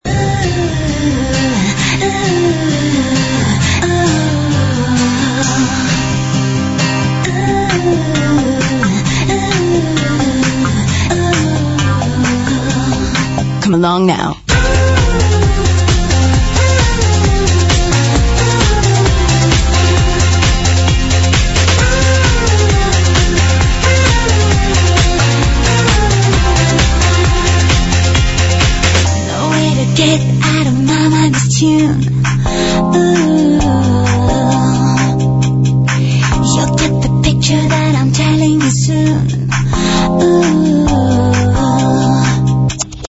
ID this utter cheese track